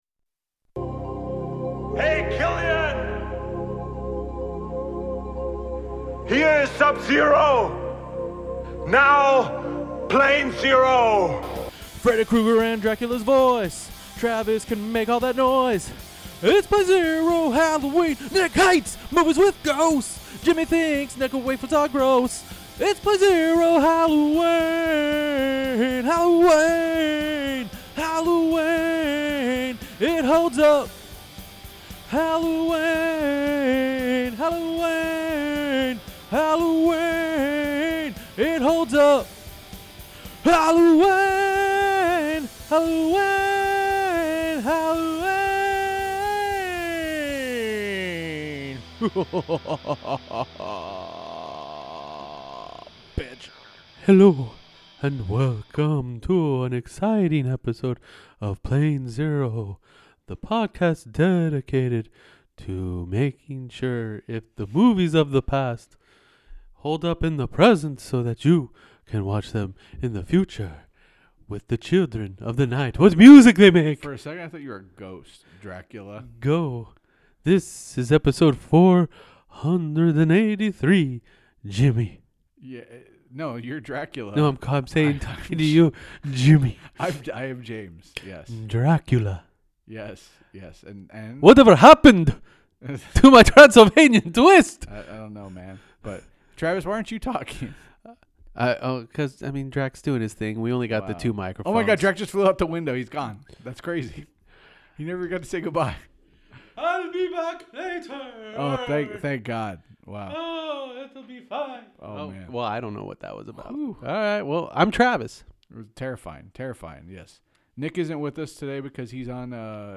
Four friends revisit a movie every week to see if it holds up and go on numerous tangents in the process!